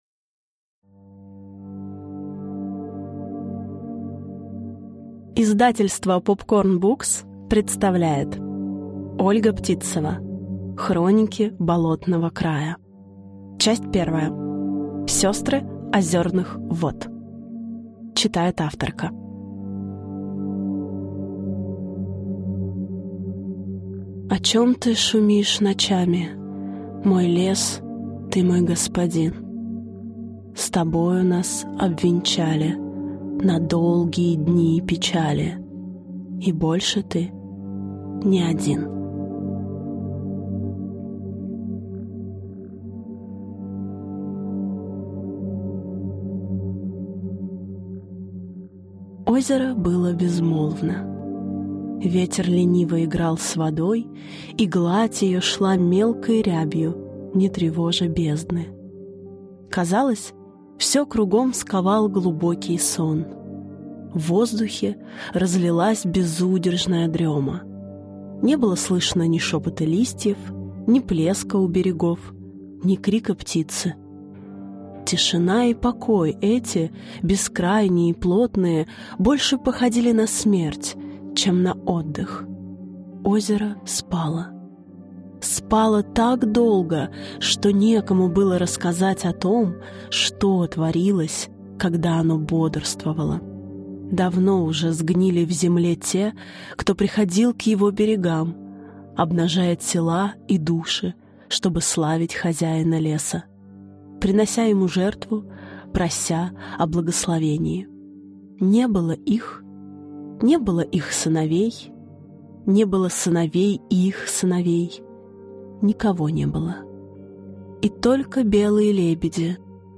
Аудиокнига Сёстры озёрных вод | Библиотека аудиокниг